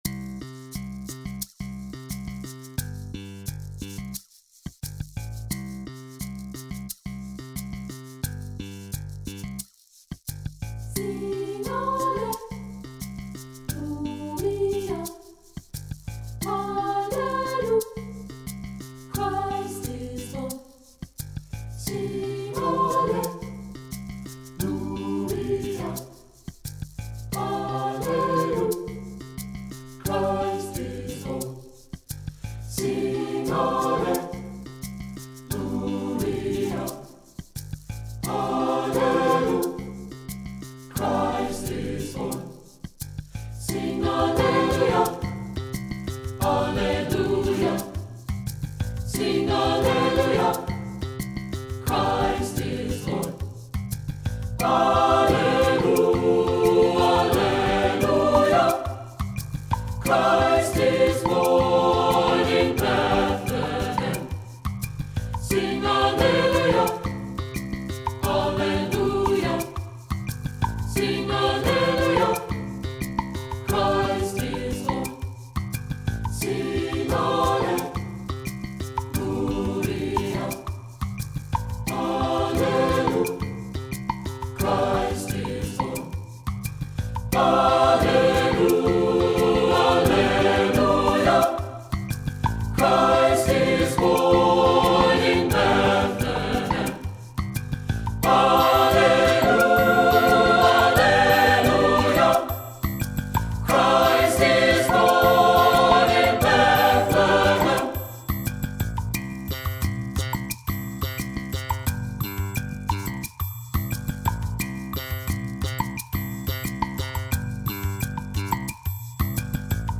SAB Church Choir Music
Voicing: TTB